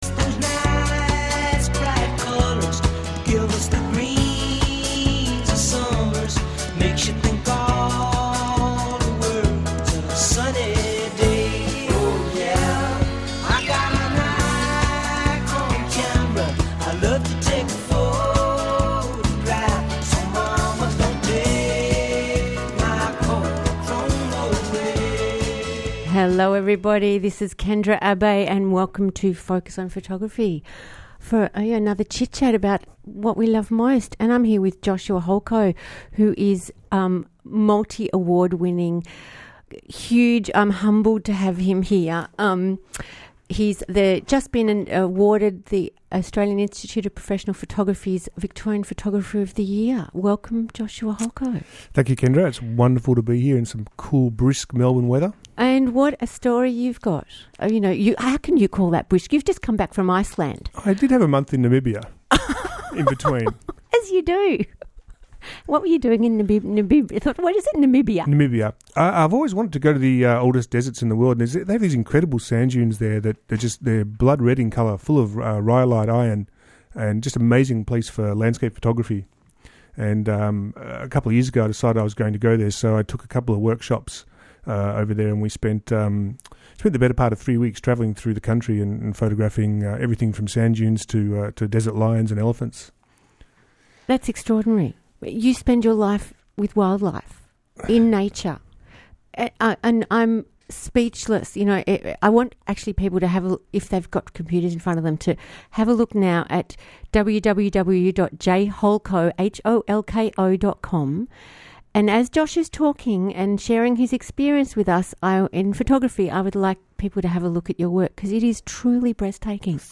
J-Air : Focus on Photography Radio Interview